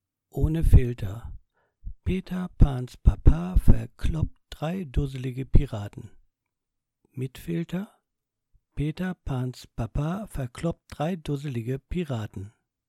Das obige Audio-File wurde mit einem Samson Q2U (ein dynamisches Mikrofon) aufgenommen, bei dem zuvor der Windschutz und das Schutzgitter entfernt wurden. Dadurch konnte der Luftstrom ungehindert auf der Mikrofonkapsel aufschlagen. Mit deutlich sicht- und hörbarem Effekt. Im ersten Teil sind etliche Lautstärke-Spitzen zu erkennen, im zweiten Teil greift ein Popschutz von König & Meyer und filtert alle Poplaute raus.
samson-ohne-filter.mp3